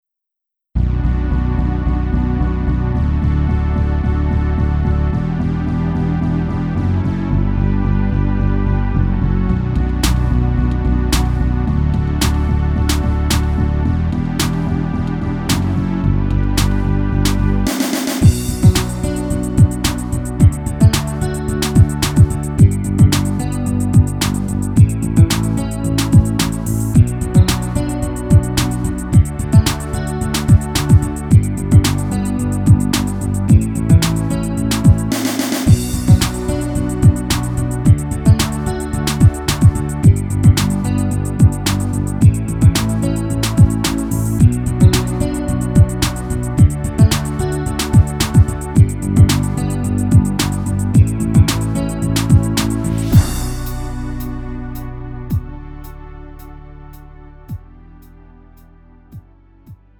가요
Lite MR